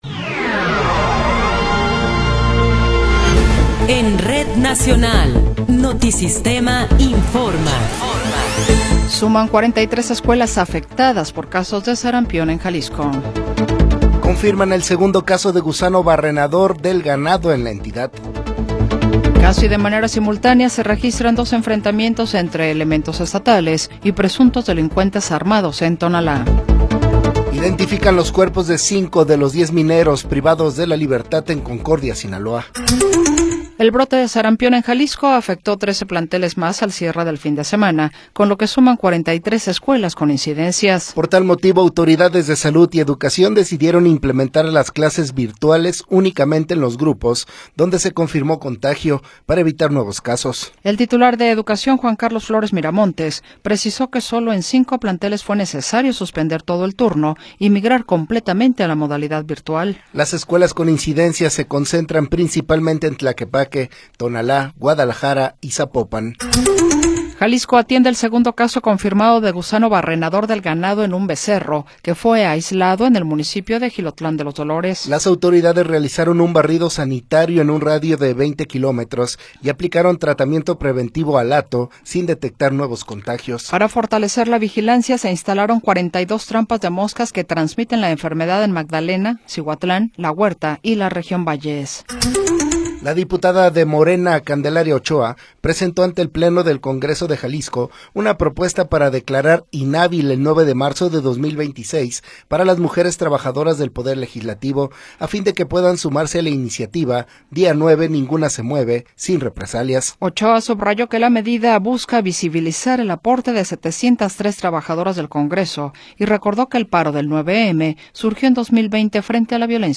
Noticiero 20 hrs. – 9 de Febrero de 2026
Resumen informativo Notisistema, la mejor y más completa información cada hora en la hora.